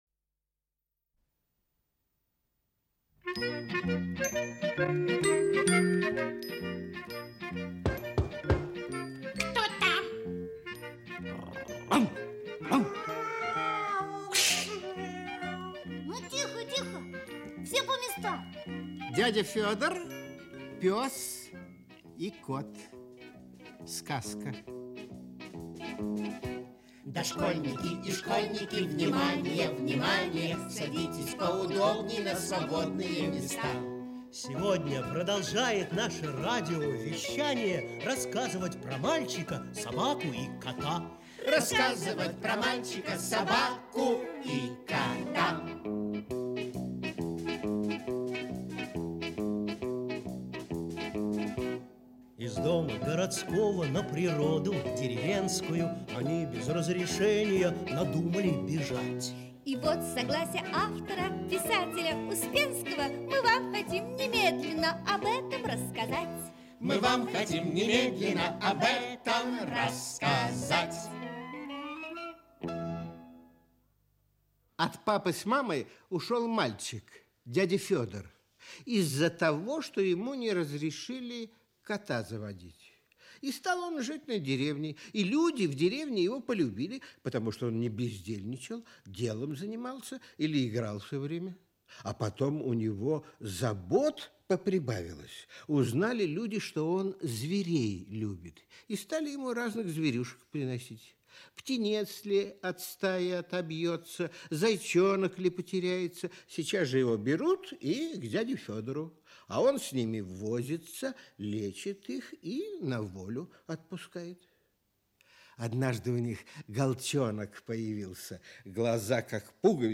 Аудиокнига Дядя Фёдор, пёс и кот (спектакль) Часть 2 | Библиотека аудиокниг
Aудиокнига Дядя Фёдор, пёс и кот (спектакль) Часть 2 Автор Эдуард Успенский Читает аудиокнигу Всеволод Абдулов.